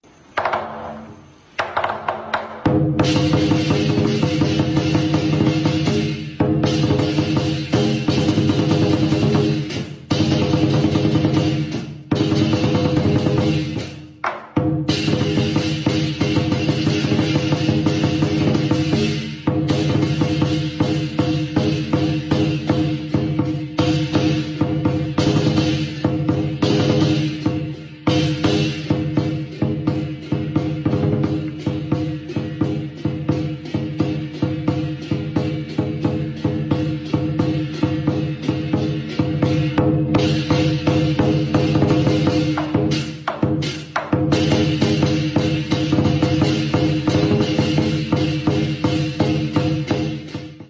Tiếng đánh trống Múa Lân Sư Rồng, Trung Thu…
Tiếng đánh trống Múa Lân, Lễ hội (chỉ có tiếng trống) Các trưởng lão vất vả quá, Xin mời… sound effect
Thể loại: Tiếng động
tieng-danh-trong-mua-lan-su-rong-trung-thu-www_tiengdong_com.mp3